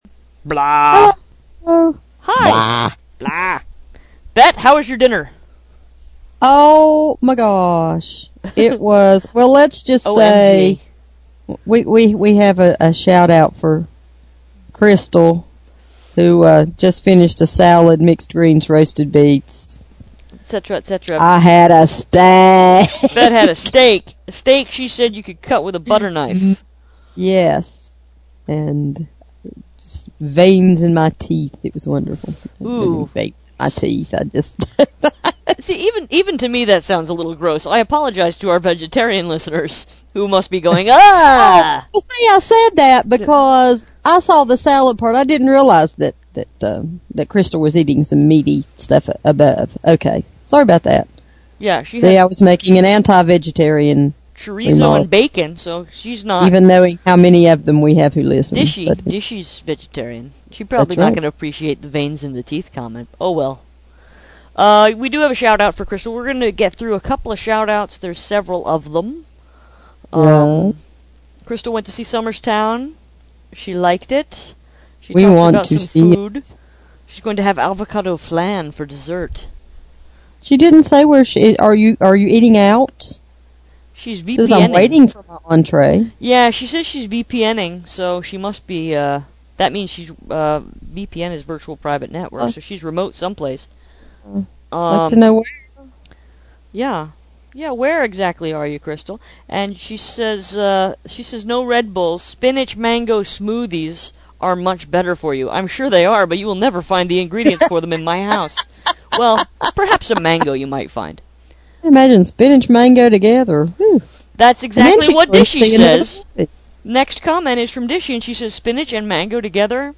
OMG, that rap was AWESOME!!!